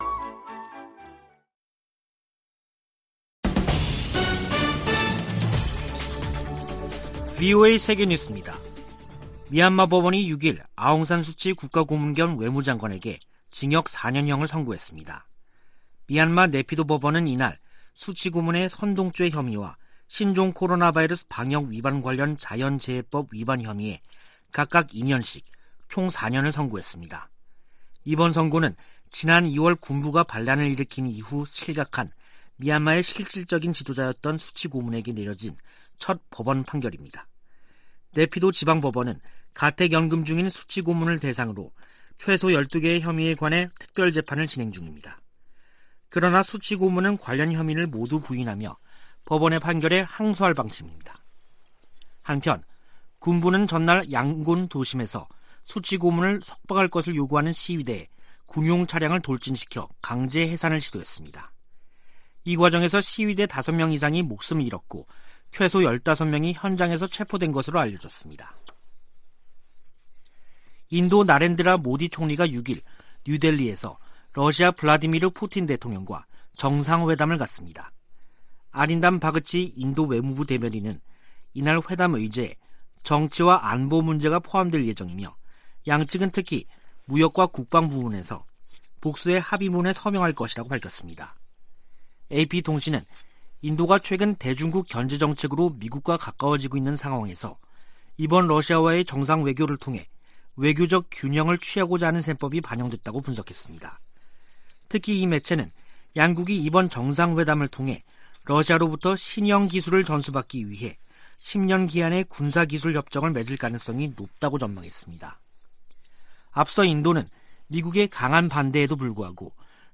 VOA 한국어 간판 뉴스 프로그램 '뉴스 투데이', 2021년 12월 6일 2부 방송입니다. 중국이 한반도 종전선언 추진에 지지 의사를 밝혔지만 북한은 연일 미국을 비난하며 냉담한 태도를 보이고 있습니다. 유엔이 올해에 이어 내년에도 북한을 인도지원 대상국에서 제외했다고 확인했습니다. 미국 유권자 42%는 조 바이든 행정부 출범 이후 미북 관계가 악화한 것으로 생각한다는 조사 결과가 나왔습니다.